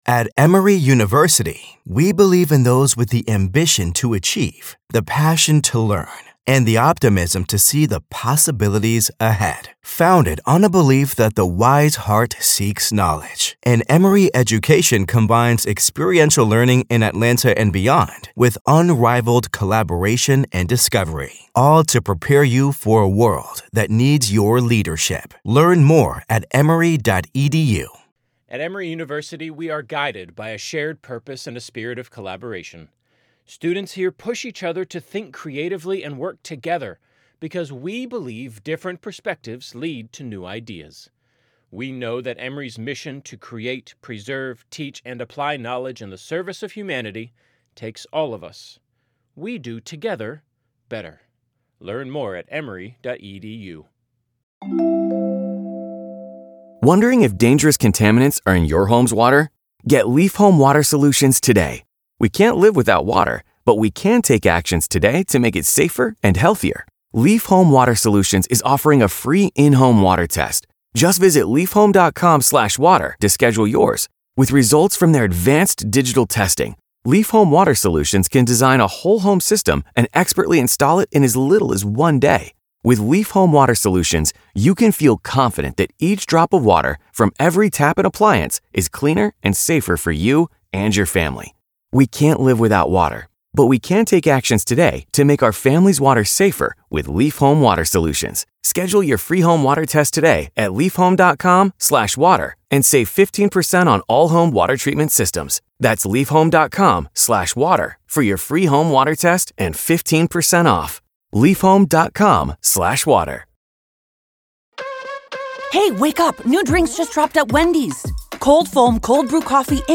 This candid conversation unravels the complex world of confidential informants (CIs), designer drugs, and the strategic maneuvers employed by federal agents to dismantle criminal networks from the bottom up.